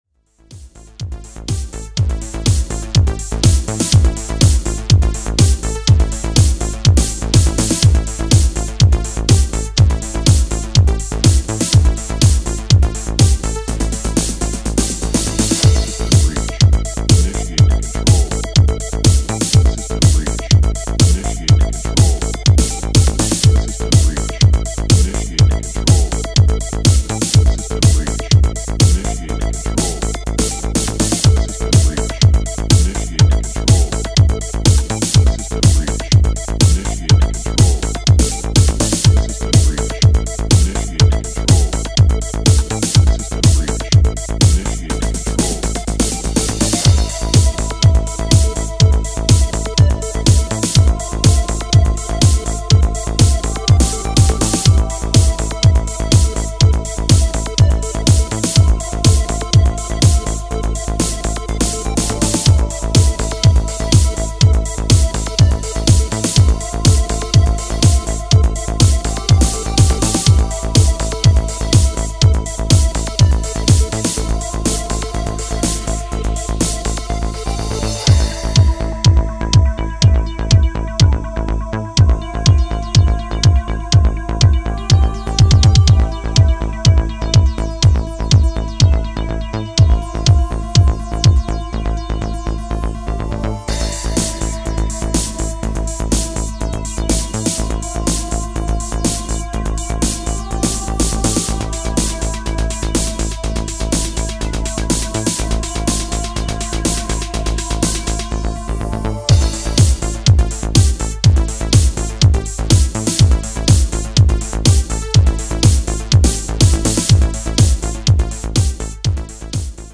アラームのようなシンセラインでダークな高揚を煽る